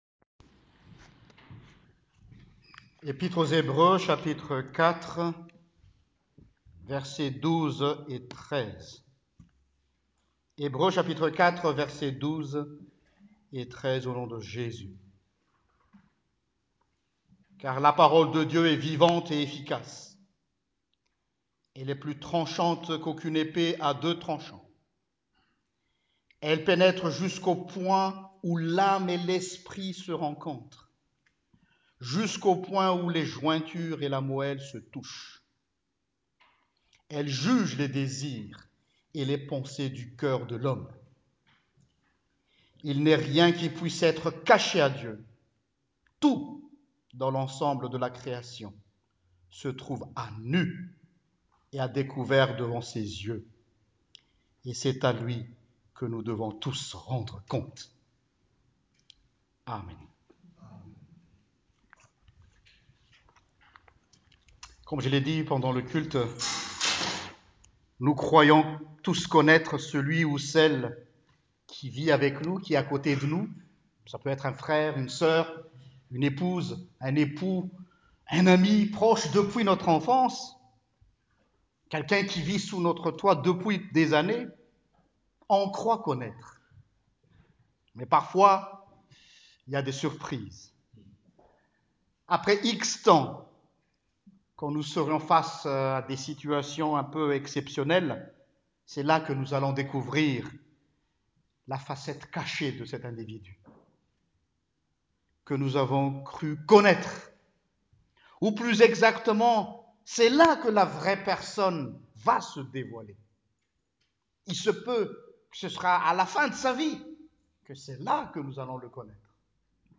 Prédication du 14 Octobre 2018: LA PAROLE DE DIEU UN SCANNER PUISSANT